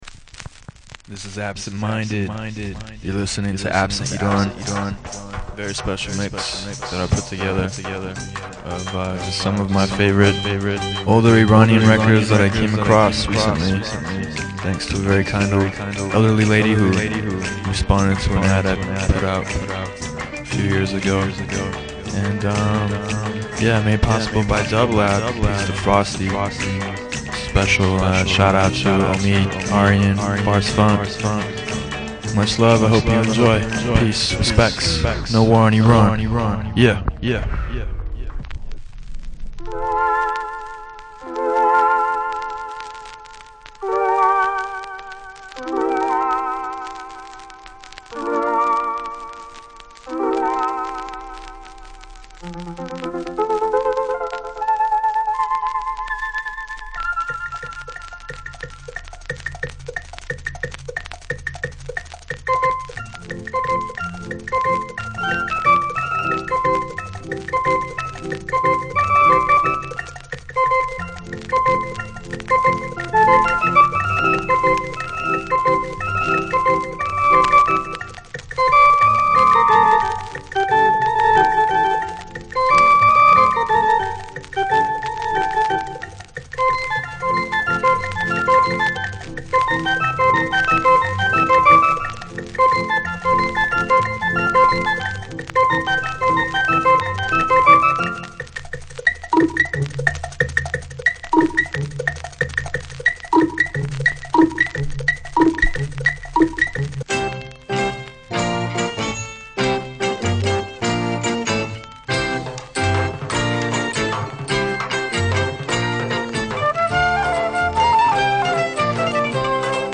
This session is the wonderful result of his search.